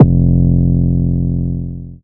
Index of /Antidote Advent/Drums - 808 Kicks
808 Kicks 12 F.wav